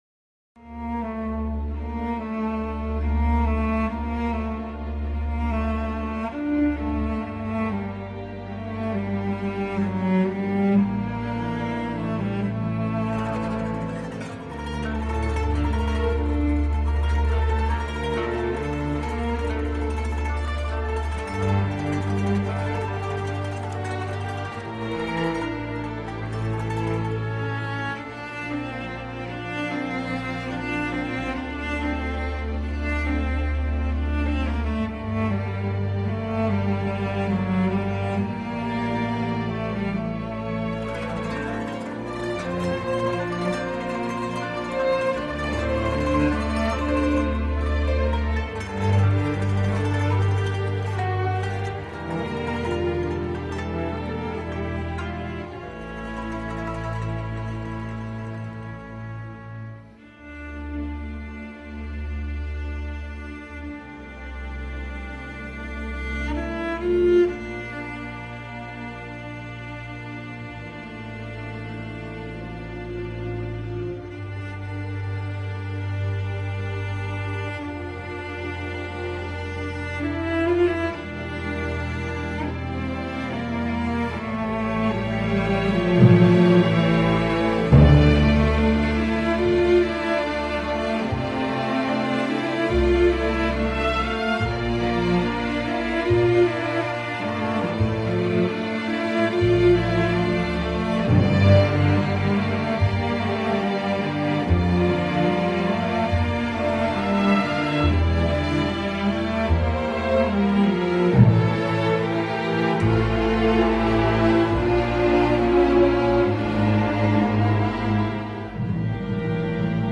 Ek Öneri (Jenerik Müzik)